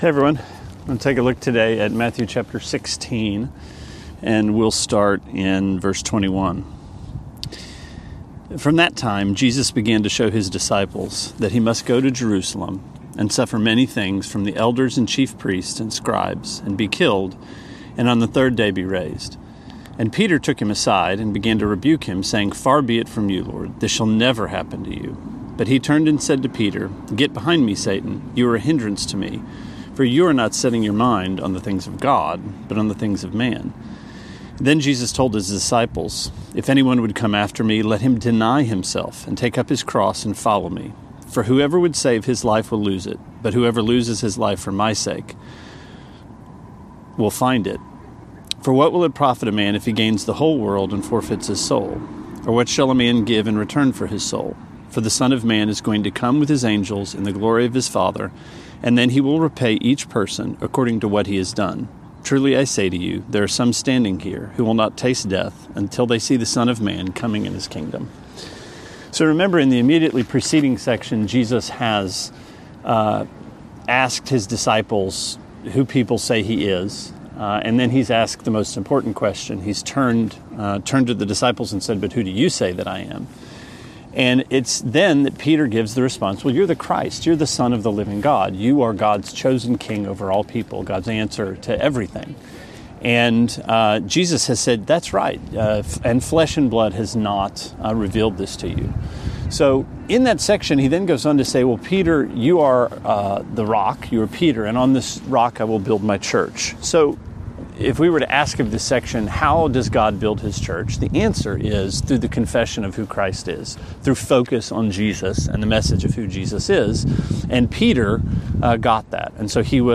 Sermonette 3/7: Matthew 16:21-29: Half Right